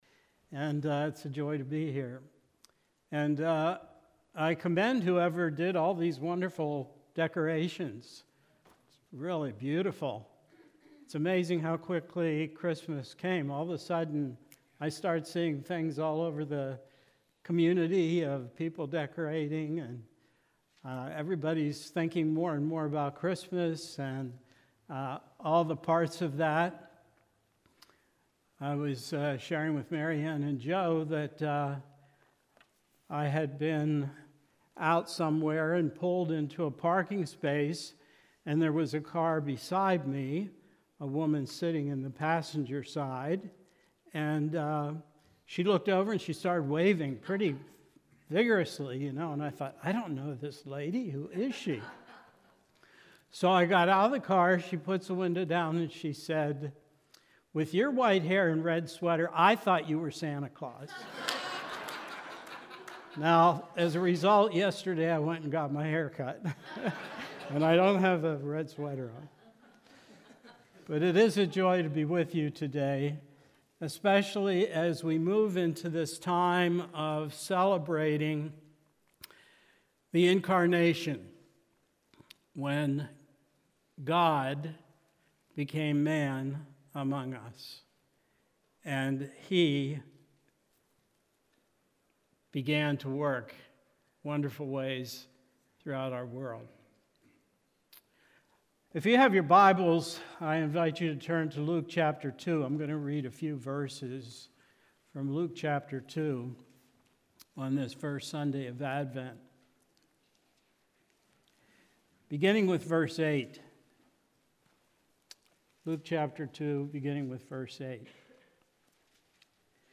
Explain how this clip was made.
Psalm 57 Service: Sunday Morning What do we do when all the foundations appear to be crumbling?